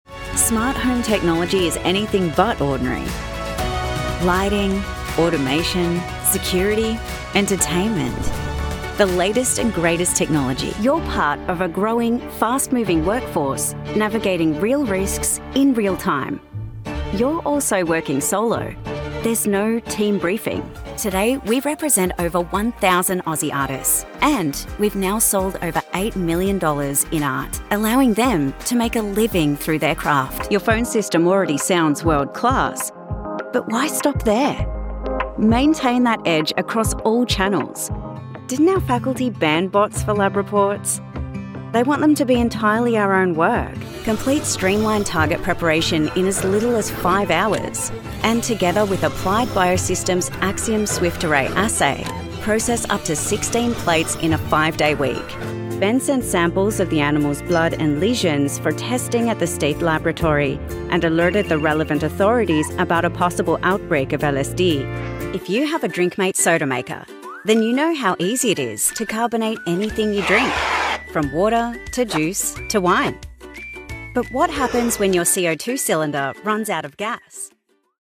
Anglais (Australien)
Vidéos d'entreprise
OPR 87 (clone Neumann U 87)
Cabine insonorisée et traitée acoustiquement